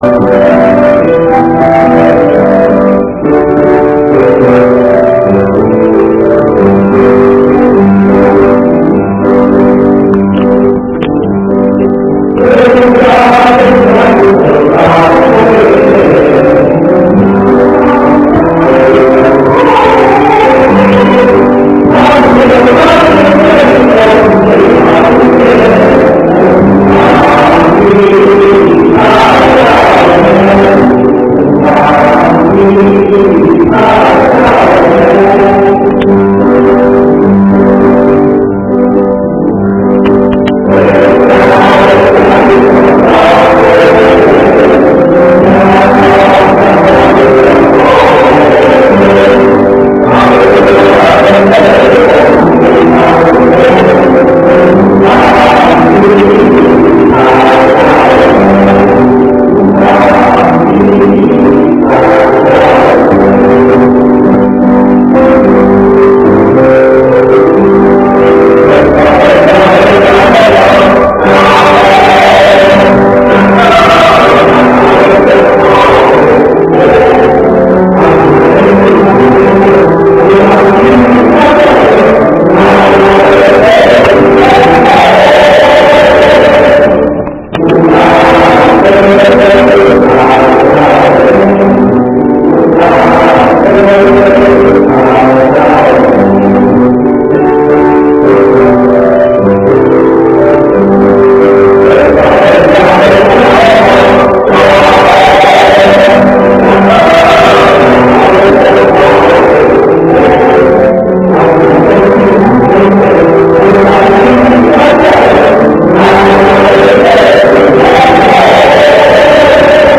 點選歌名下方各聲部可聽到各聲部個別旋律( 均有鋼琴作伴奏, 該聲部聲音以各種不同樂器聲音表達, 以便有別於鋼琴伴奏音 )
火車十八輪(混聲四部) 故鄉的鳳凰花(混聲四部) 板橋查某(混聲四部)